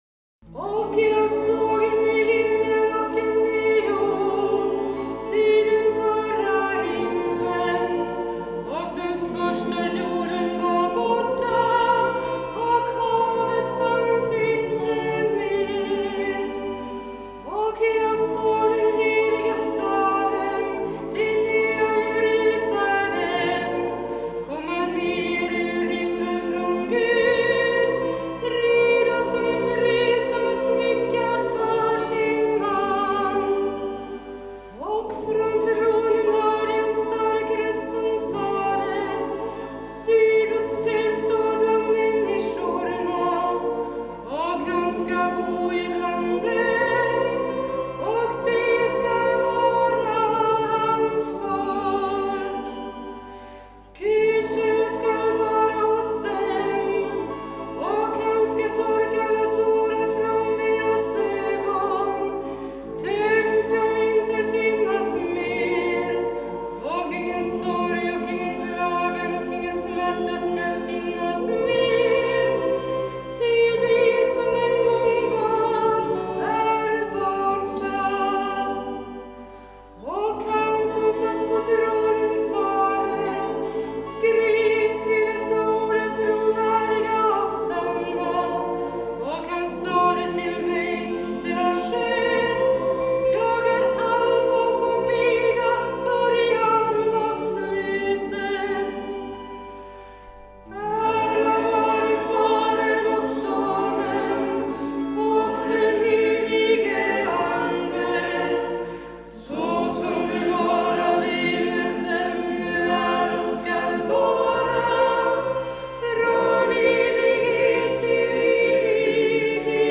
systrar tillsammans med vänner